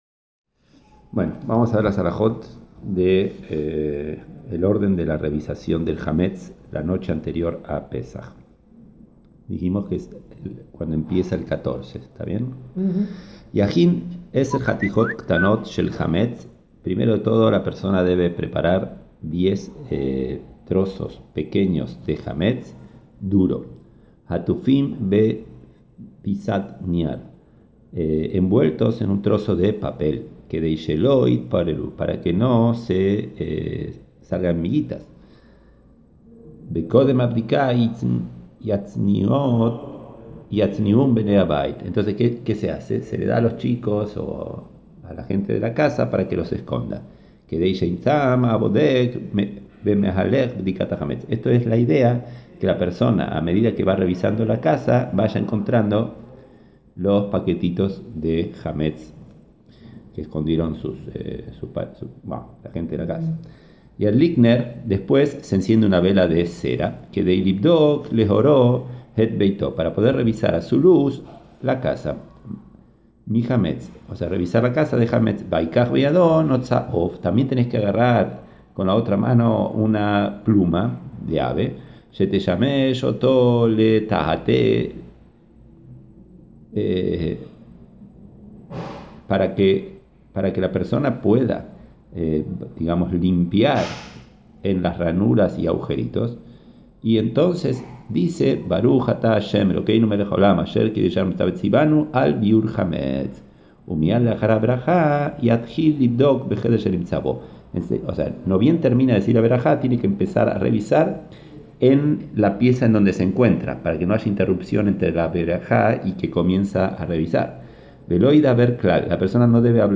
Ya están disponibles para escuchar las Halajot de preparación para Pesaj, desde la limpieza y casherización hasta el Seder mismo. Clases